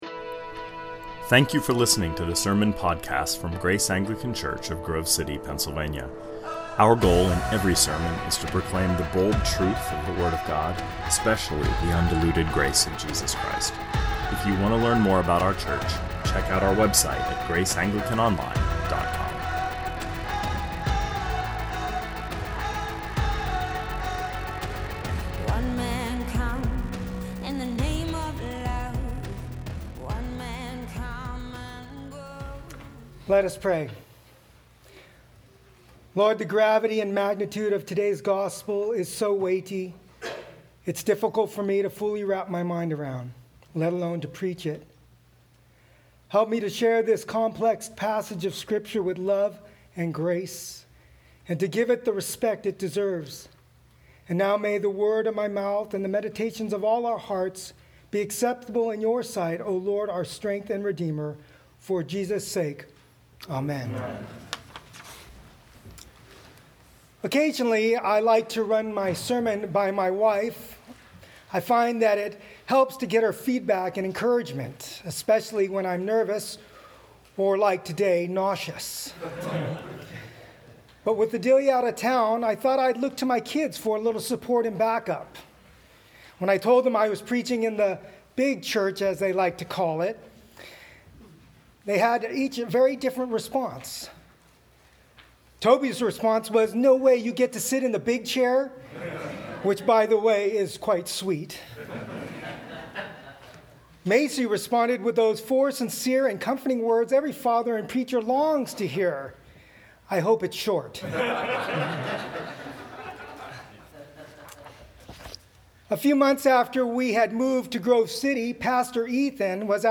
2020 Sermons The One Victory that Matters -Matthew 4 Play Episode Pause Episode Mute/Unmute Episode Rewind 10 Seconds 1x Fast Forward 30 seconds 00:00 / 18:56 Subscribe Share RSS Feed Share Link Embed